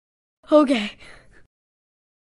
Exhausted Girl Says Okay sound effect
Thể loại: Âm thanh meme Việt Nam
exhausted-girl-says-okay-sound-effect-www_tiengdong_com.mp3